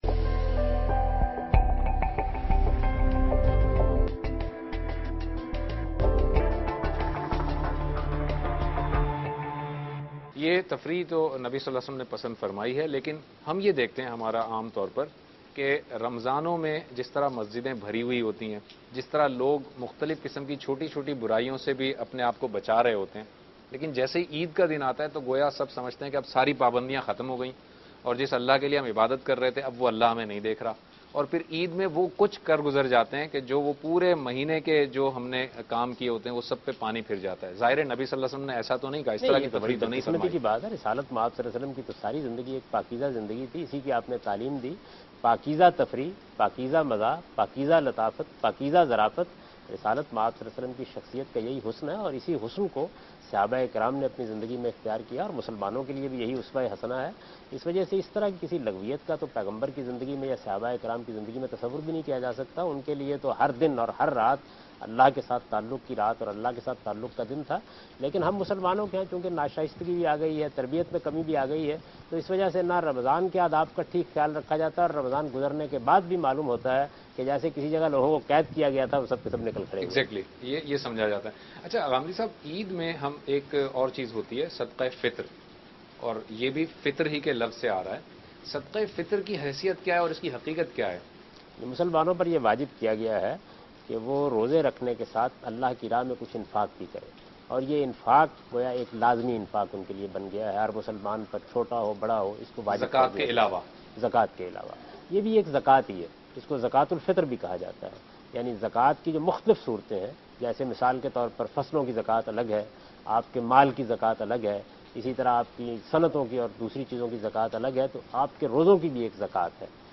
Dunya Tv program Deen-o-Danish Special. Topic: Eid Aur Hamari Zimedariyan.
دنیا ٹی وی کے پروگرام دین ودانش میں جاوید احمد غامدی عید اور ہماری ذمہ داریوں کے متعلق گفتگو کر رہے ہیں